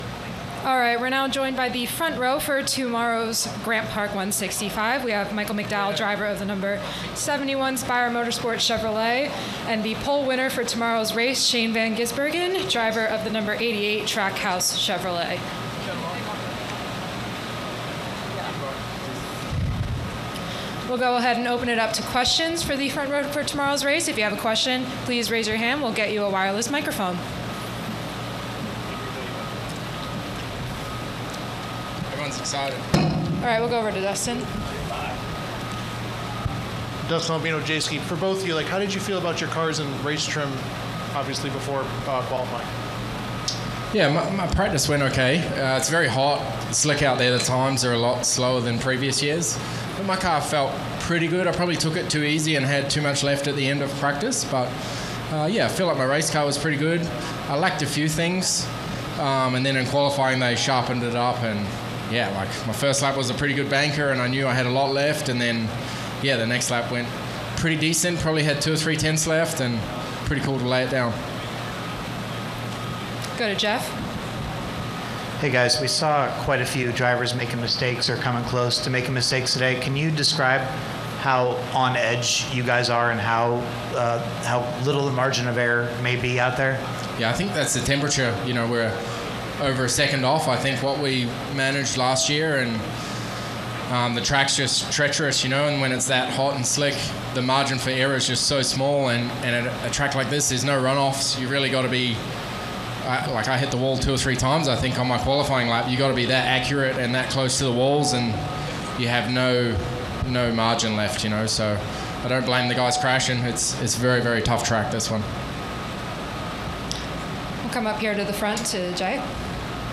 Interviews:
Cup Series front row Shane van Gisbergen (No. 88 Trackhouse Racing Chevrolet) and Michael McDowell (No. 71 Spire Motorsports Chevrolet) –